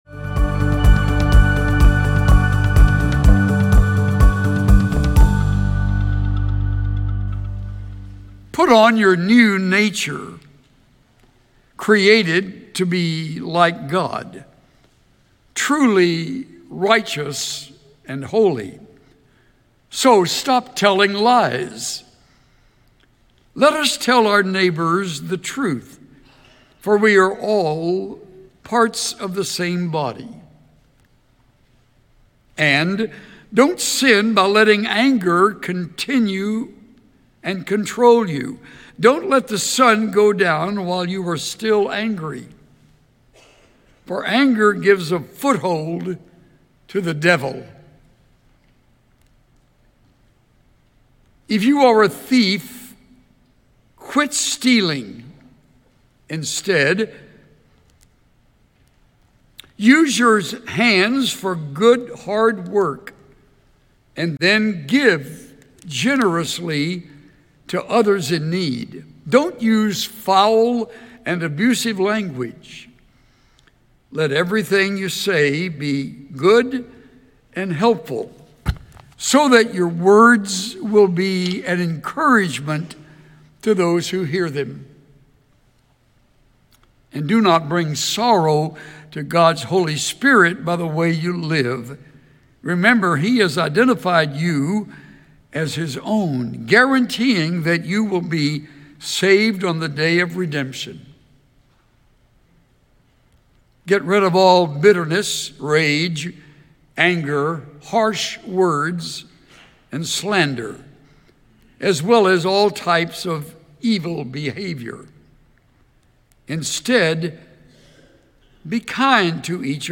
Be encouraged by this time of worship as we remember that we’re made in God’s image and are called to reflect His Character.
Listen to Message